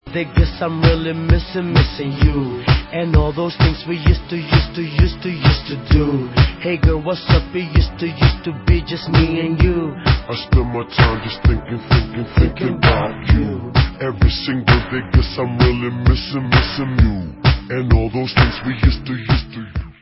sledovat novinky v oddělení Dance/Hip Hop